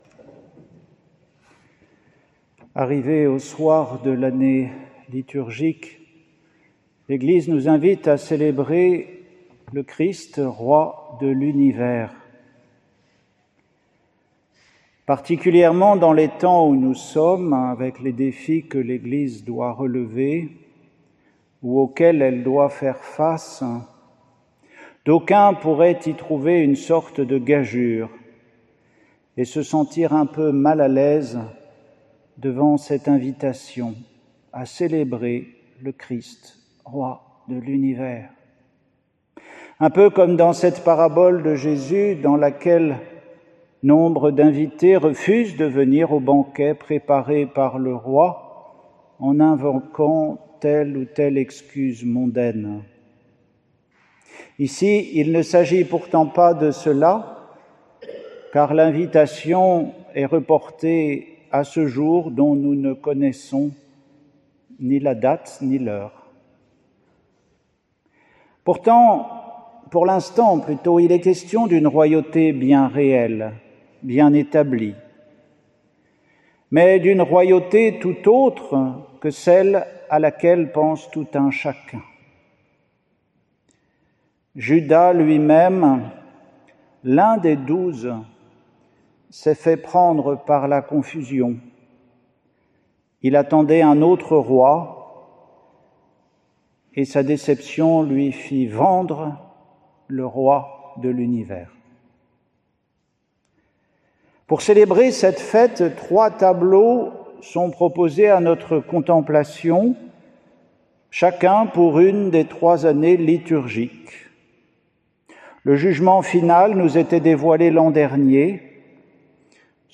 Homélie pour la solennité du Christ-Roi, 24 novembre 2024